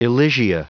Prononciation du mot elysia en anglais (fichier audio)